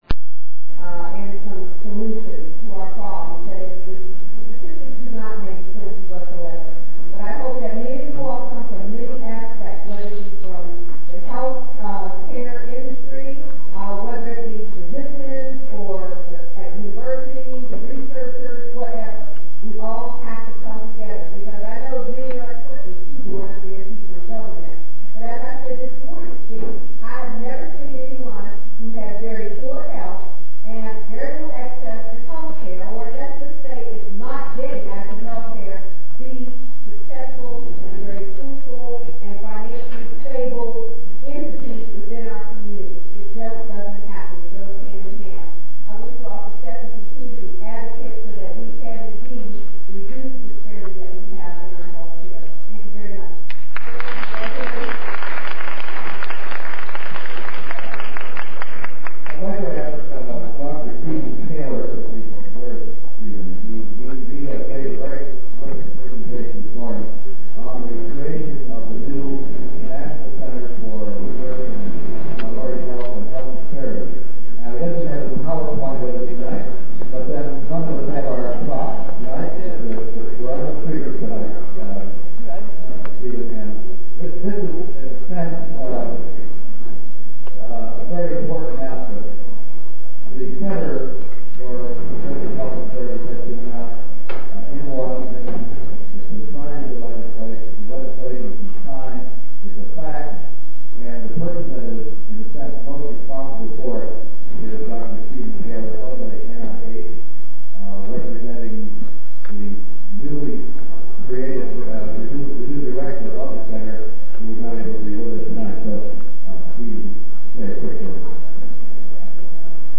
MP3 audio files recorded by the Pennsylvania and Ohio Public Health Training Center at the First Annual Minority Health Leadership Summit